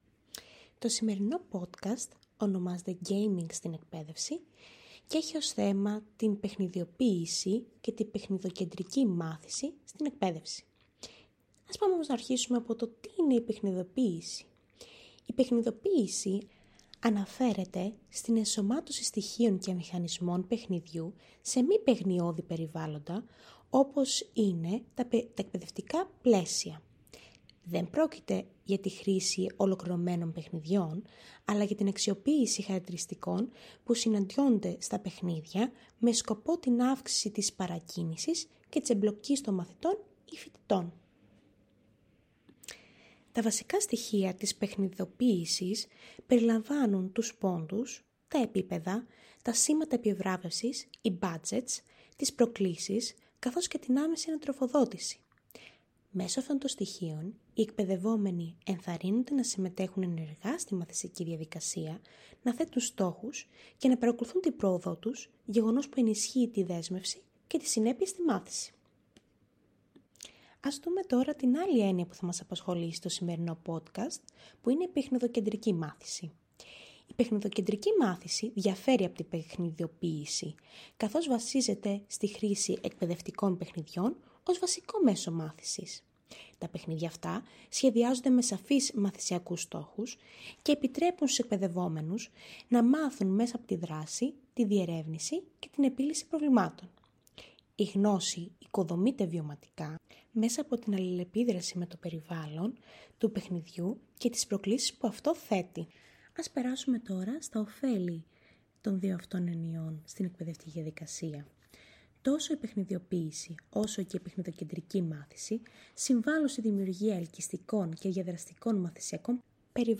Στην παρούσα ενότητα παρουσιάζεται ένα εκπαιδευτικό Podcast διάρκειας έως 5 λεπτών, το οποίο αναπτύχθηκε με βάση τις αρχές του εκπαιδευτικού project οπτικοακουστικής παραγωγής και με βάση την προτεινόμενη μεθοδολογία για τη δημιουργία παιδαγωγικά τεκμηριωμένων ψηφιακών αφηγήσεων (Παπαδημητρίου, 2022)
Το παρόν podcast έχει τη μορφή προσωπικής αφήγησης και έχει ένα ομιλητή, ο οποίος εστιάζει στον ορισμό της παιχνιδοποίησης και της παιχνιδοκεντρικής μάθησης, στα βασικά χαρακτηριστικά τους, στα οφέλη που προσφέρουν στην εκπαίδευση καθώς και σε ενδεικτικά ψηφιακά εργαλεία που υποστηρίζουν την εφαρμογή τους.